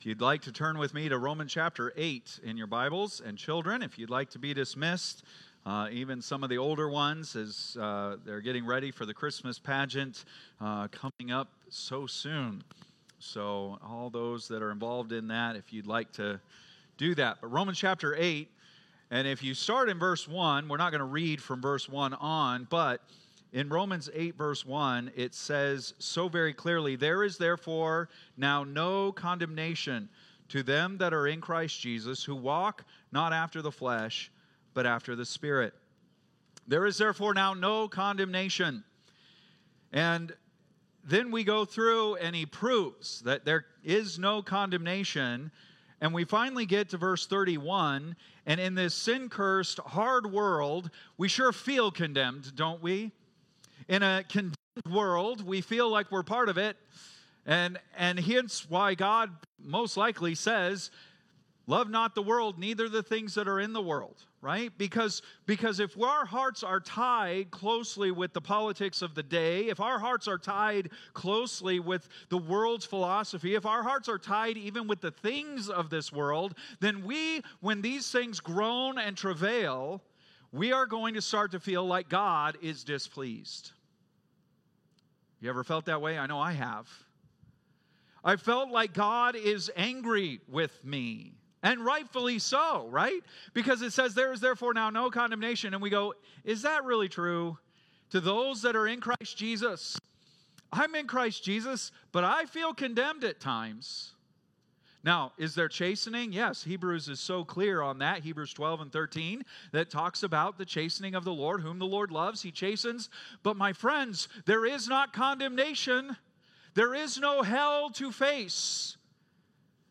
Date: November 17, 2024 (Sunday Morning)